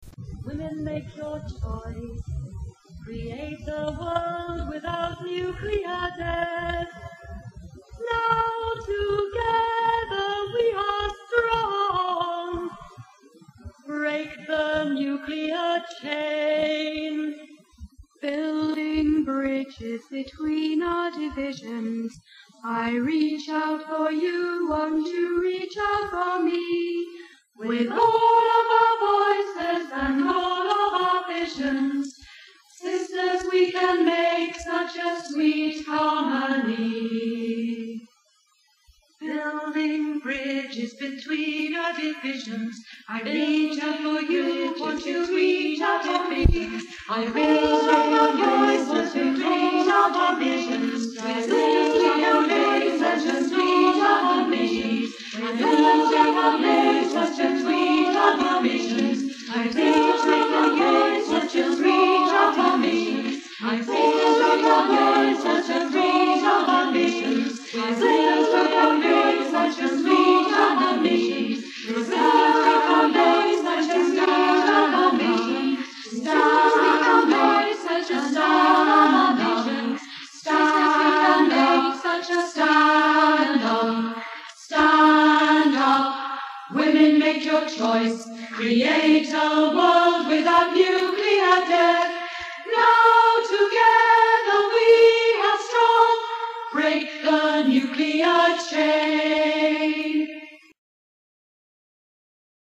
sung by 1world Peacesingers.
Music: Contemporary English Quaker Round